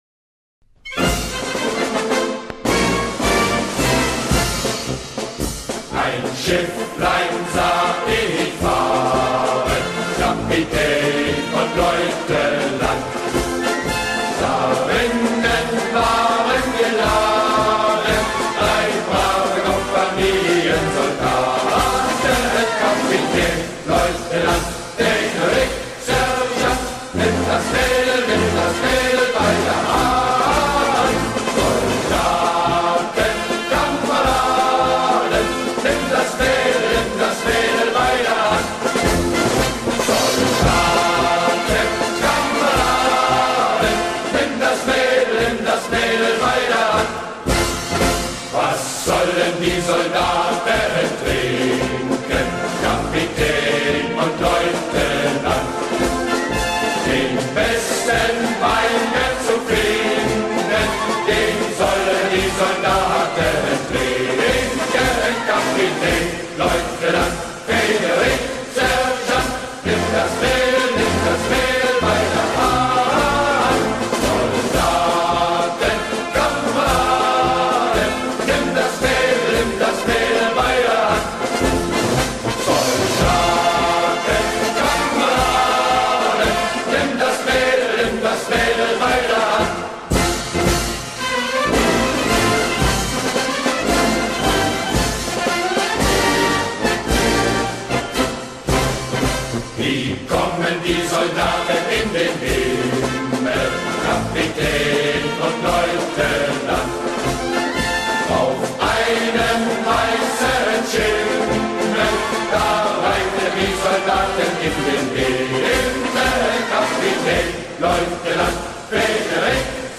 10 German Marching Songs